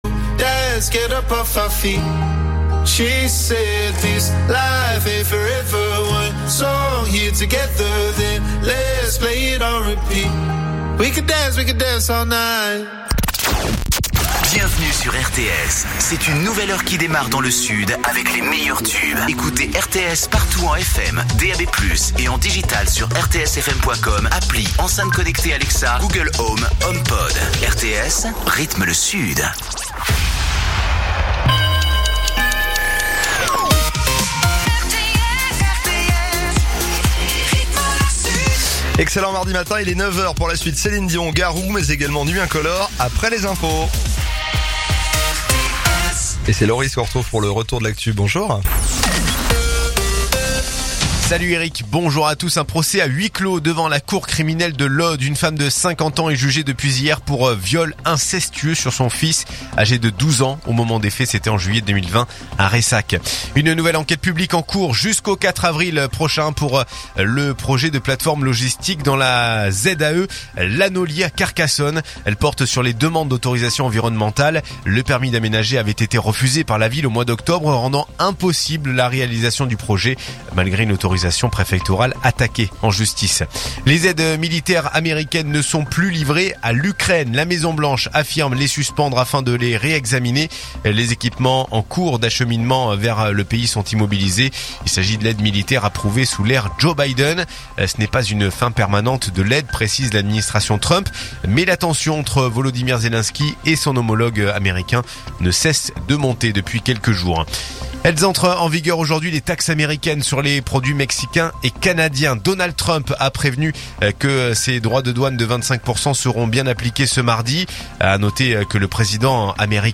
info_narbonne_toulouse_312.mp3